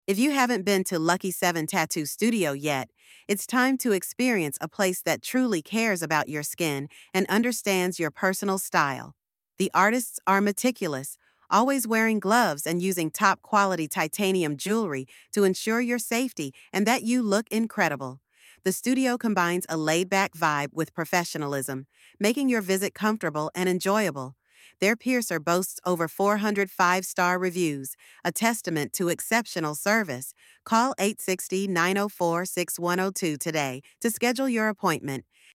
Discover how Lucky Seven Tattoo Studio's expert artists and piercers deliver impeccable service and attention to detail, ensuring every client feels valued and safe. Featuring real studio visuals and voiceover insights on our commitment to quality and care.
74671-voiceover.mp3